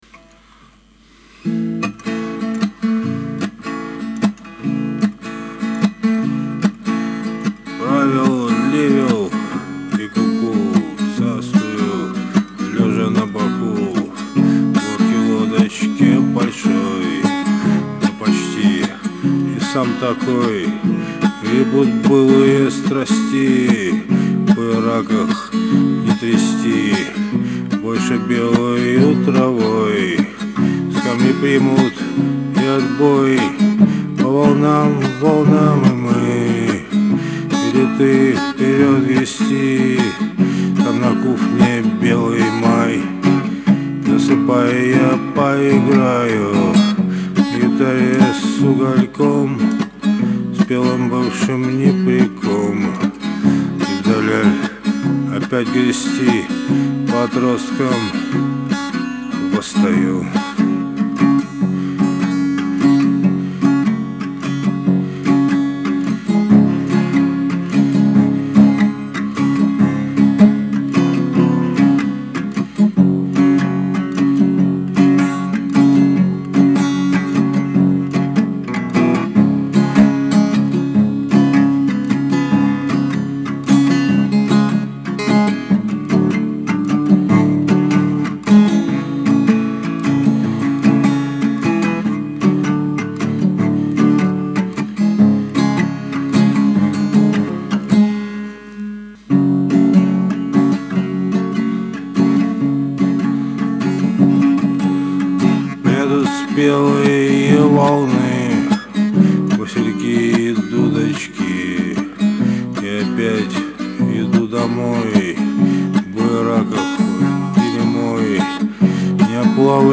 улучшил усилитель**** * * * *
вот моя(перепел сейчас)